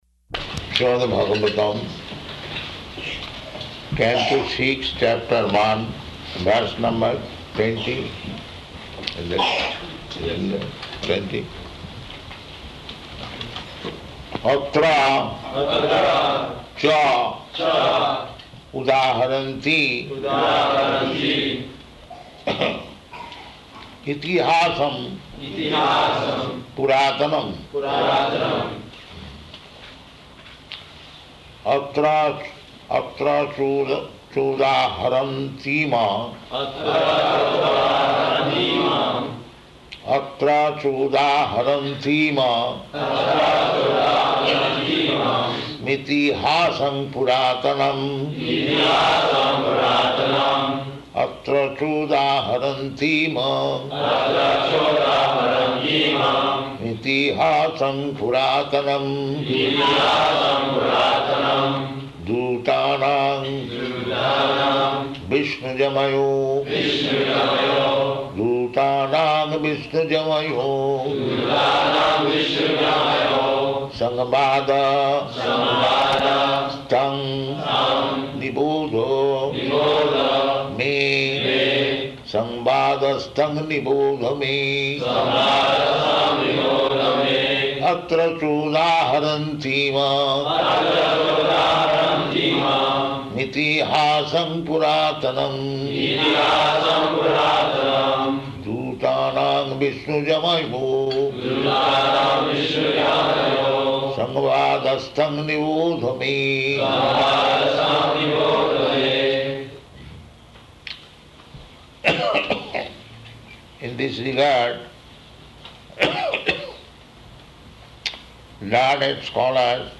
Śrīmad-Bhāgavatam 6.1.20 --:-- --:-- Type: Srimad-Bhagavatam Dated: May 20th 1976 Location: Honolulu Audio file: 760520SB.HON.mp3 Prabhupāda: Śrīmad-Bhāgavatam, Canto Six, Chapter One, verse number twenty?